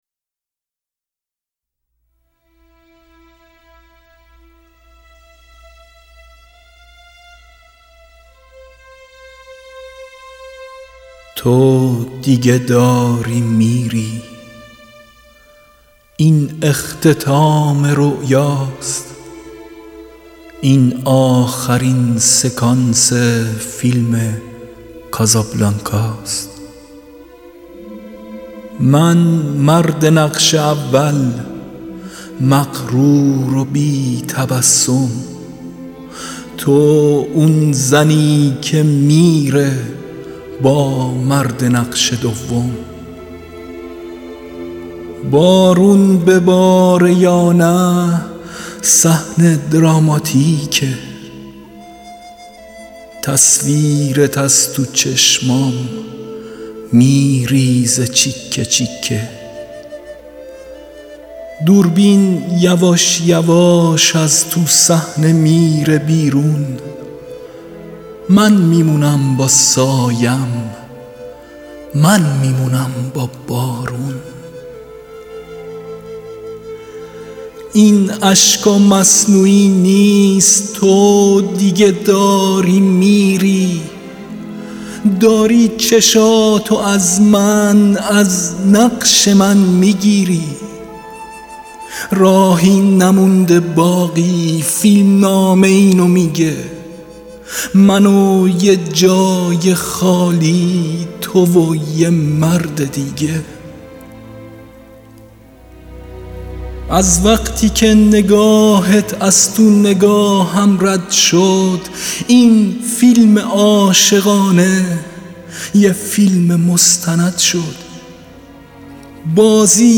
دانلود دکلمه کازابلانکا باصدای یغما گلرویی
گوینده :   [یغما گلرویی]